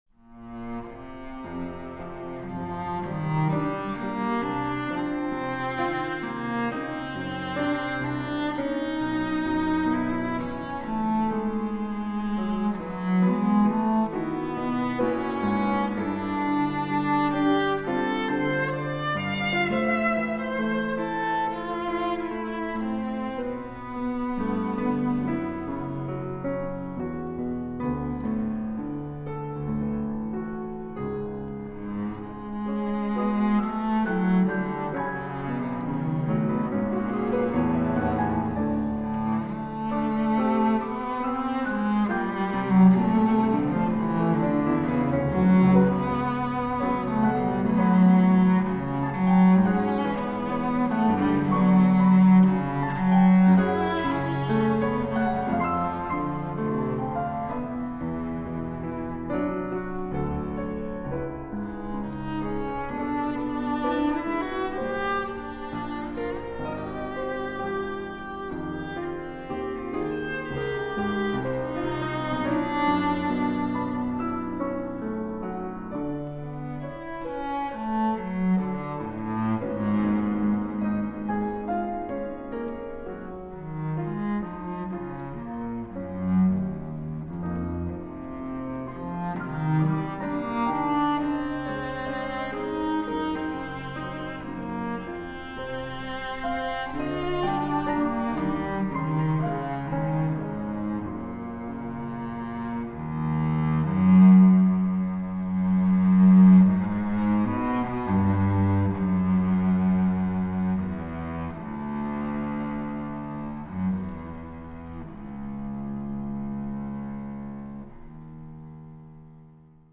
Chamber Ensembles